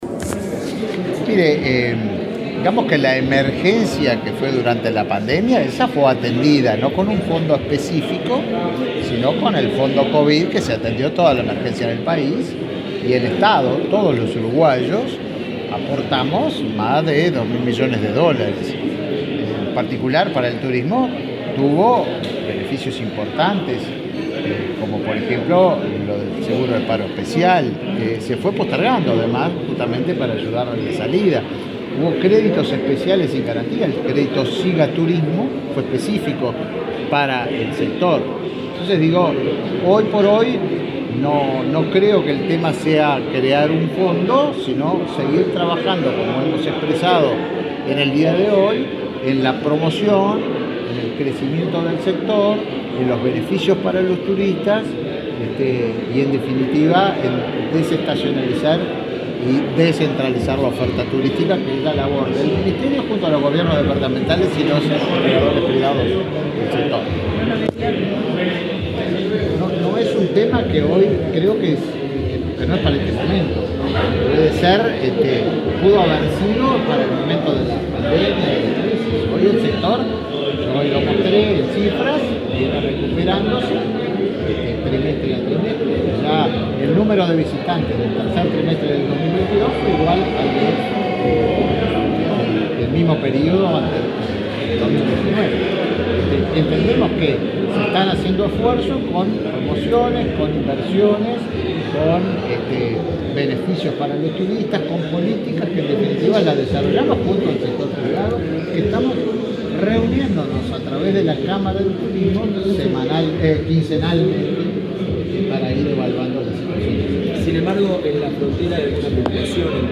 Declaraciones a la prensa del ministro de Turismo, Tabaré Viera
Declaraciones a la prensa del ministro de Turismo, Tabaré Viera 08/11/2022 Compartir Facebook X Copiar enlace WhatsApp LinkedIn El ministro de Turismo, Tabaré Viera, disertó este martes 8 en Montevideo, en un almuerzo de la Asociación de Dirigentes de Marketing y, luego, dialogó con la prensa.